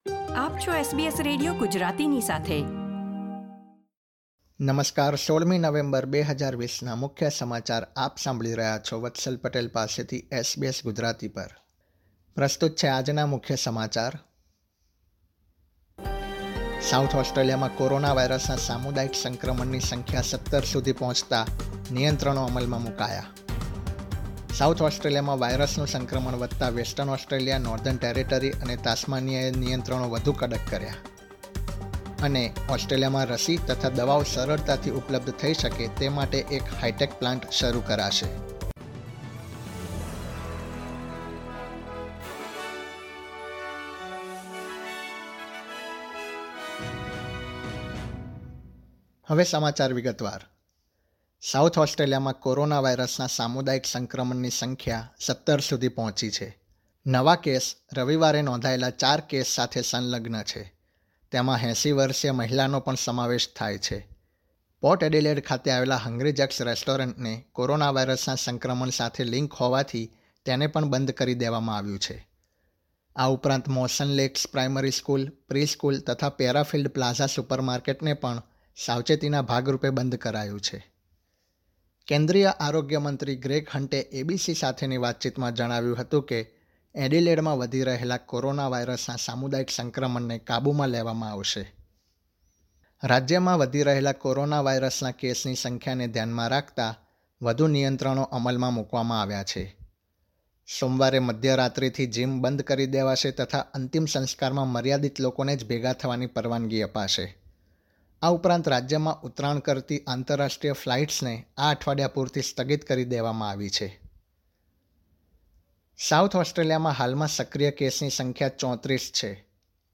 SBS Gujarati News Bulletin 16 November 2020
gujarati_1611_newsbulletin.mp3